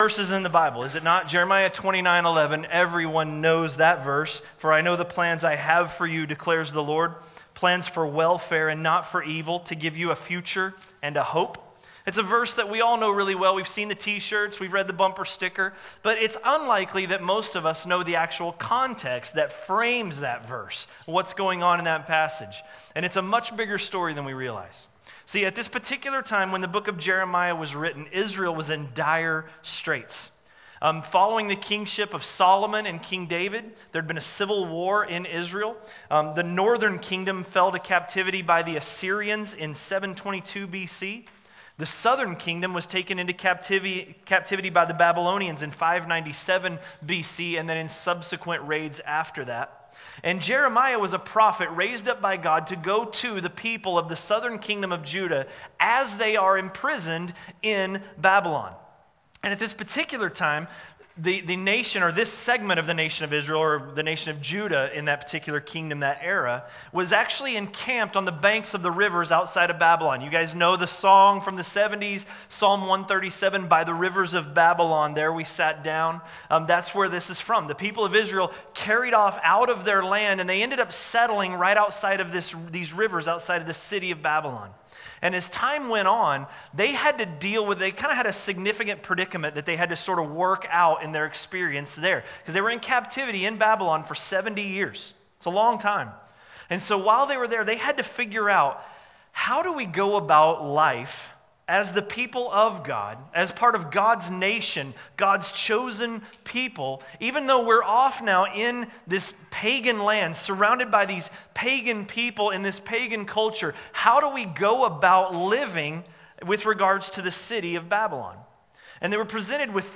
A message from the series "Topical Message." Jeremiah 29:4–29:11